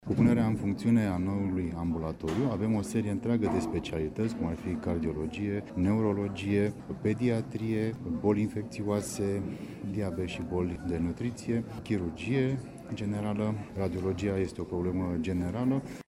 Ca să ajute unitățile sanitare să-și acopere deficitul de doctori, Direcția de Sănătate Publică din Constanța a organizat, ieri, la Facultatea de Medicină a Universității Ovidius o întâlnire între medicii aflați în căutarea unui loc de muncă, reprezentanții spitalelor și cei ai autorităților locale și județene din Dobrogea.